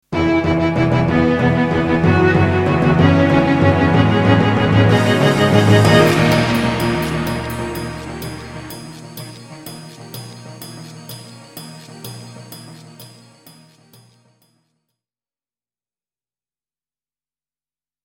Sterke intromuziek met uitloop